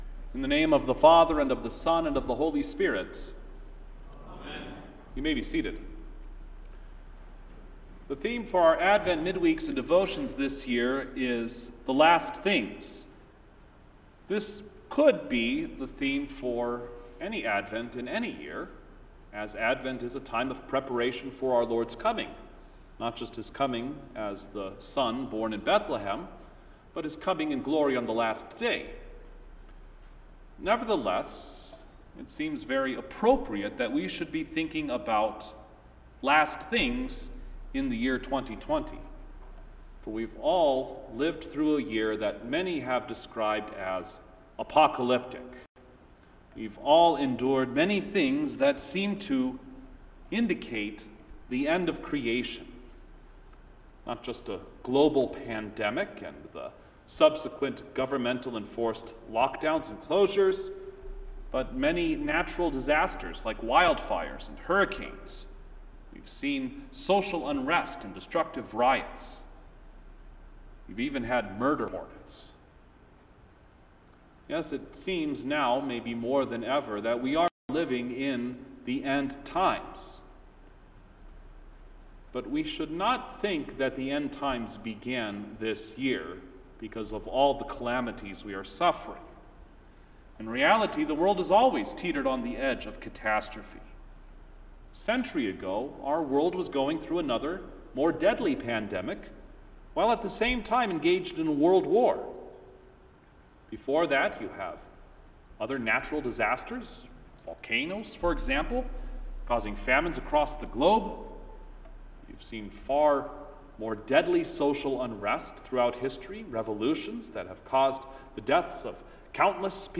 December-2-Advent-Midweek-Service-1_Sermon.mp3